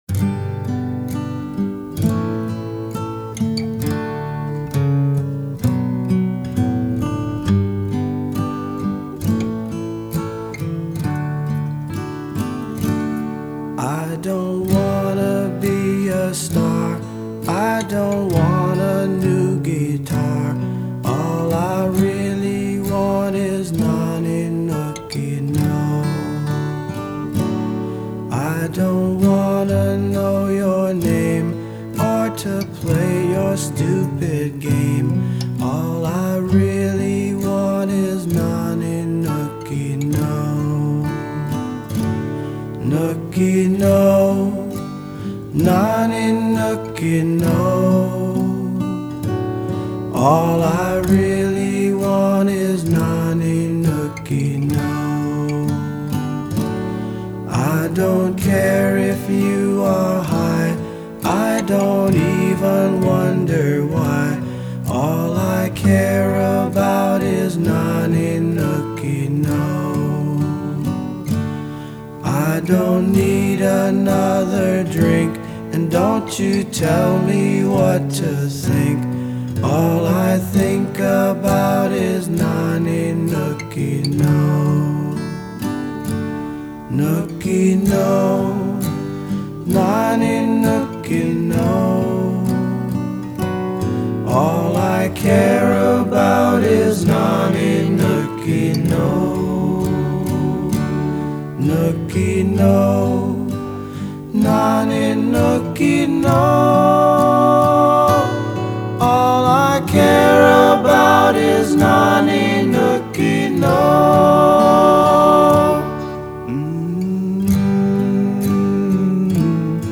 pub rock